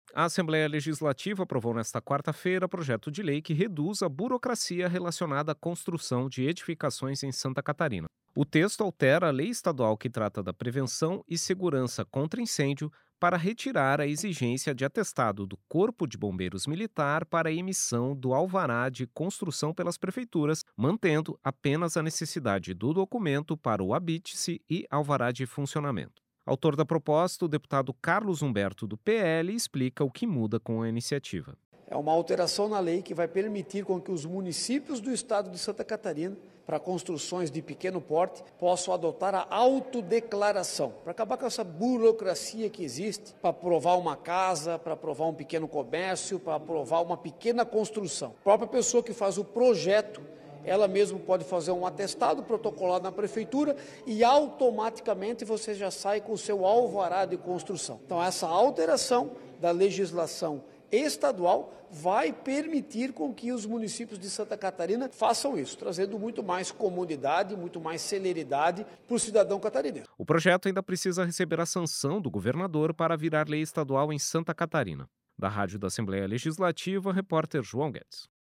Entrevista com:
- deputado Carlos Humberto (PL), autor do projeto de lei.